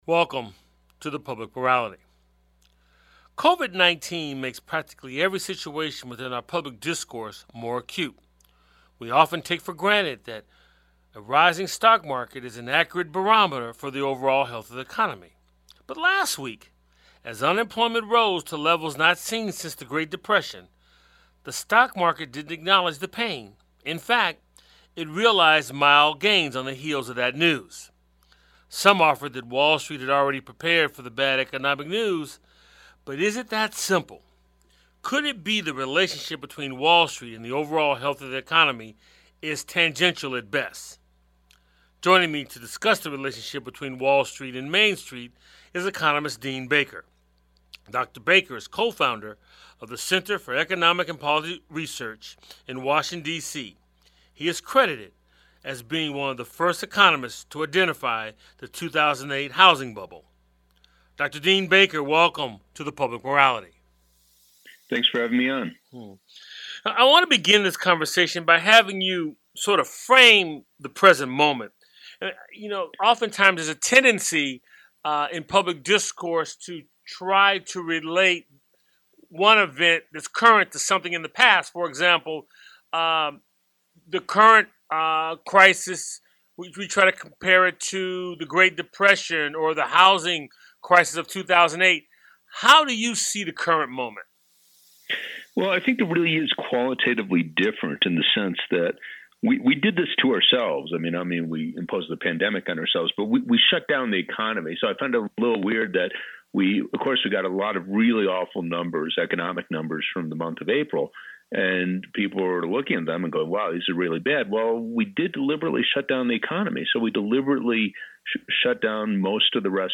Guest on this show is Economist Dean Baker.&nbsp; Public Morality is a one-hour public affairs talk show